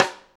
high rim shot f.wav